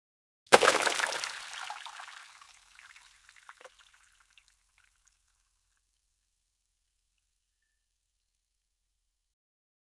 Fish cutting
fish-cutting--mazuoauz.wav